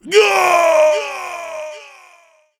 PlayerDeath.wav